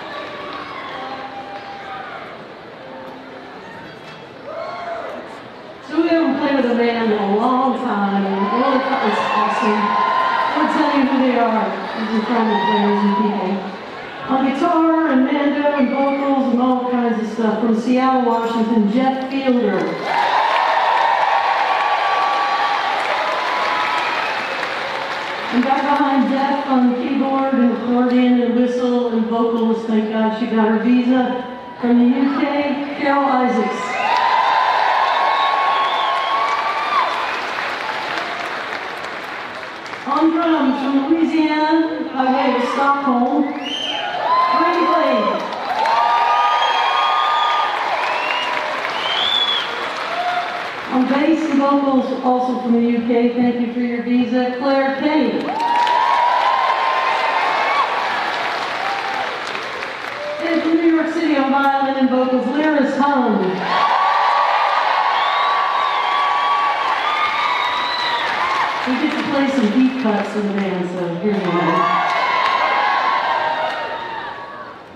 lifeblood: bootlegs: 2023-06-09: cadence bank amphitheatre at chastian park - atlanta, georgia
(band show)
04. band introductions (1:14)